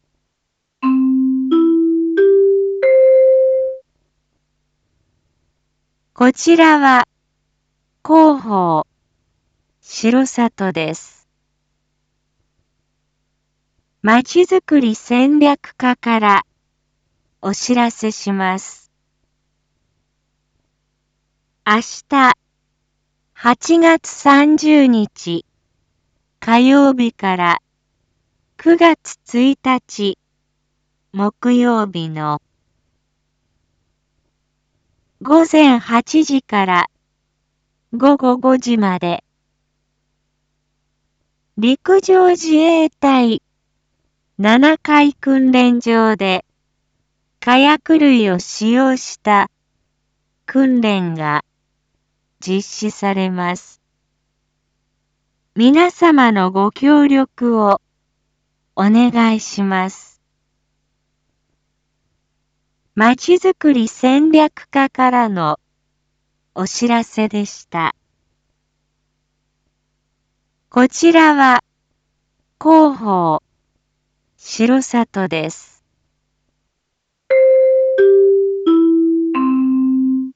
一般放送情報
Back Home 一般放送情報 音声放送 再生 一般放送情報 登録日時：2022-08-29 19:01:21 タイトル：R4.7．31 19時放送分 インフォメーション：こちらは広報しろさとです。